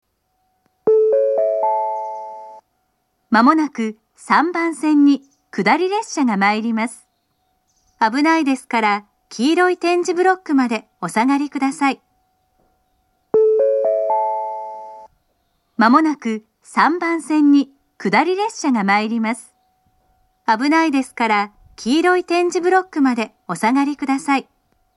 接近放送の流れるタイミングは遅めで、通過列車の場合放送の最後に列車が通過してしまいます。
接近放送前のチャイムが上下で異なるのはそのままです。
３番線下り接近放送